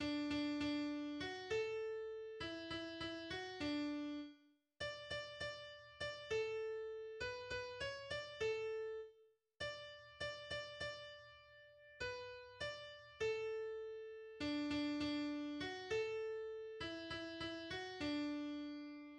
Piano recording of the first verse